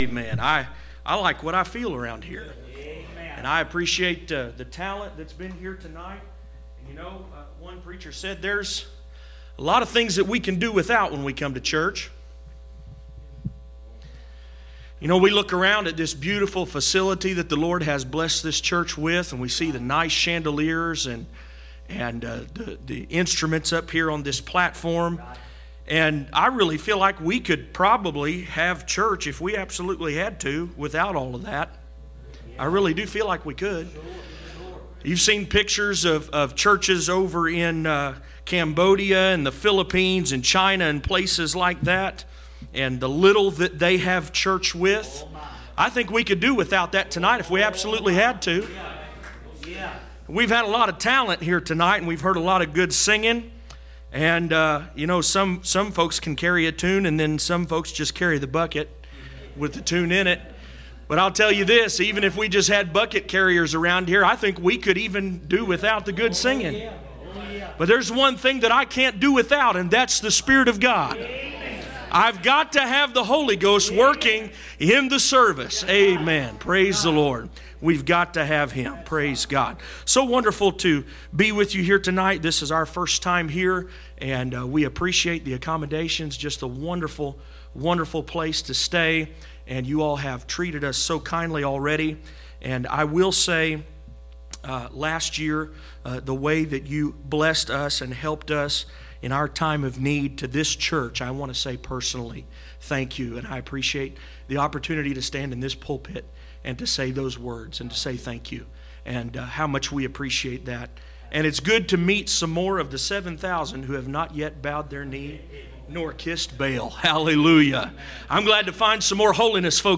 None Passage: Romans 6:23 Service Type: Special event %todo_render% « Christianity on Fire